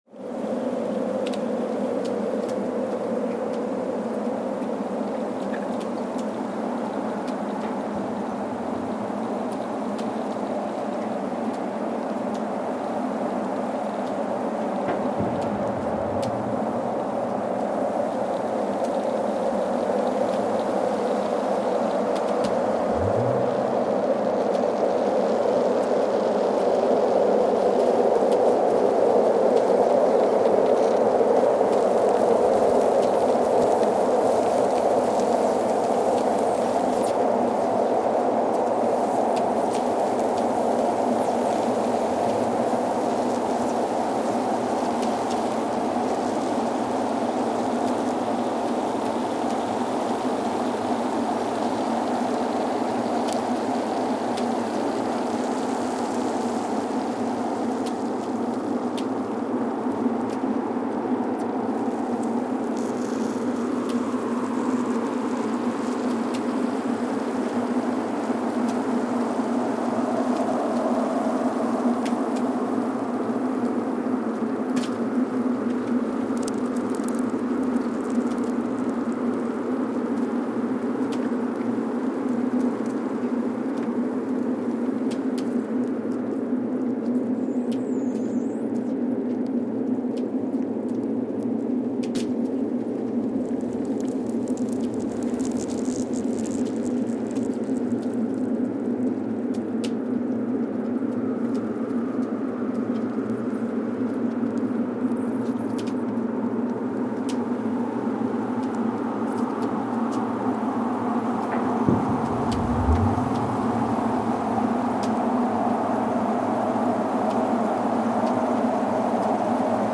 Casey, Antarctica (seismic) archived on September 15, 2020
Sensor : Streckheisen STS-1VBB
Speedup : ×1,800 (transposed up about 11 octaves)
Loop duration (audio) : 05:36 (stereo)
Gain correction : 25dB
SoX post-processing : highpass -2 90 highpass -2 90